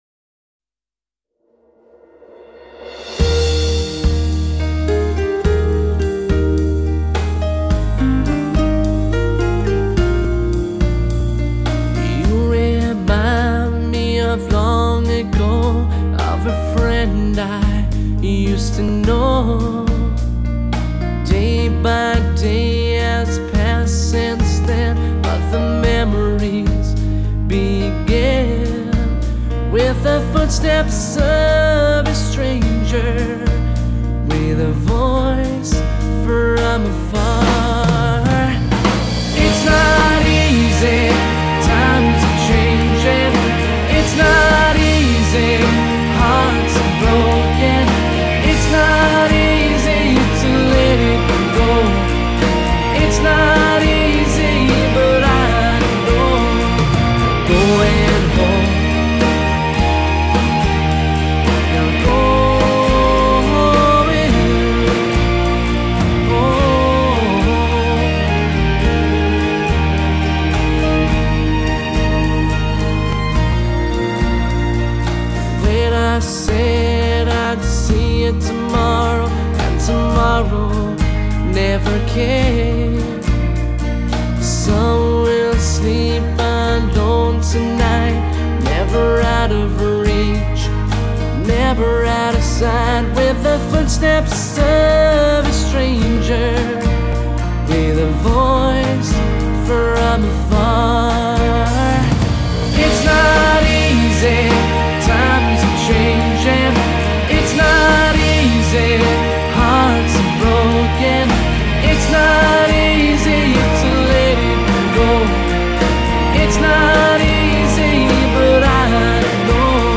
sung by Irish singer